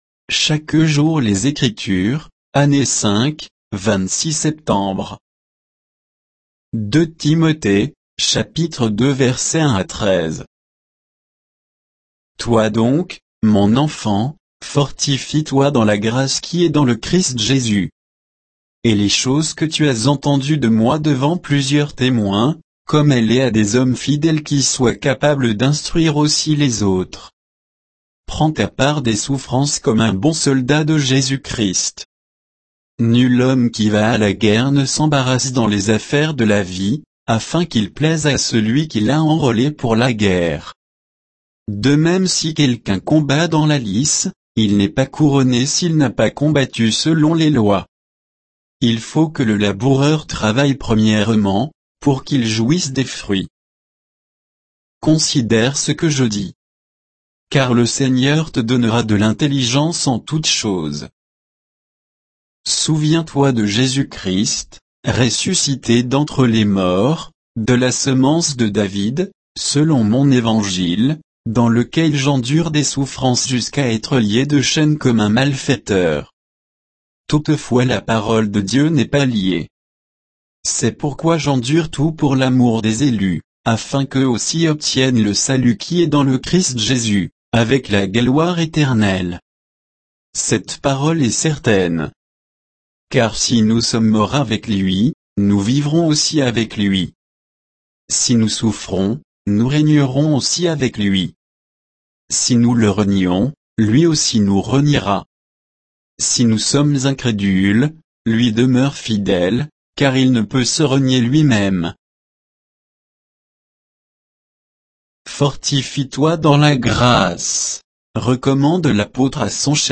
Méditation quoditienne de Chaque jour les Écritures sur 2 Timothée 2